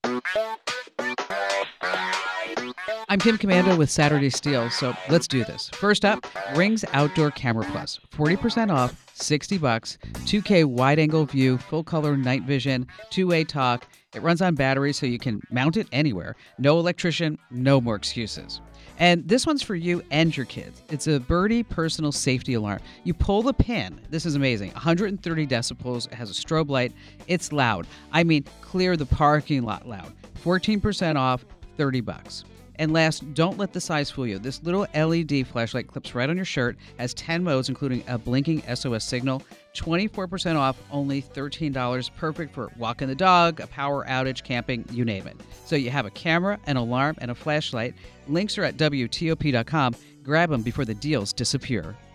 Kim Komando breaks downs some of the top ‘Saturday Steals’